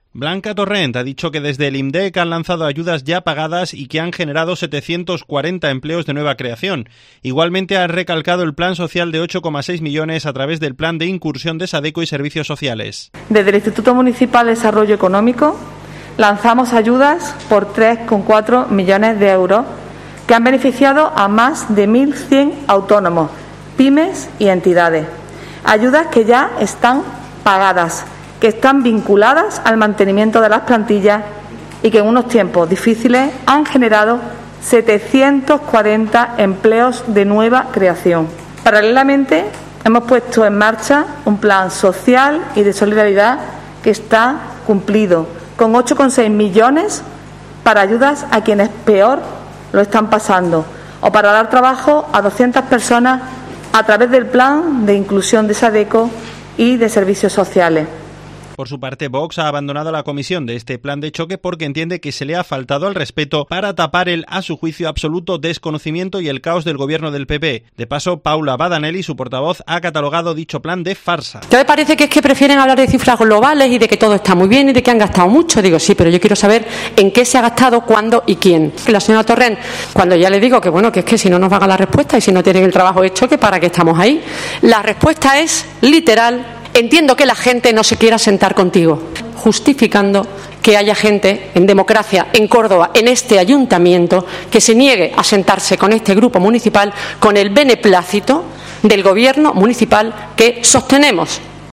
En una rueda de prensa, la edil ha dicho que se le ha "faltado el respeto" a ella personalmente y al grupo que representa para "tapar el absoluto desconocimiento y el caos del gobierno del PP incapaz de explicar un Plan de Choque que ha sido una farsa".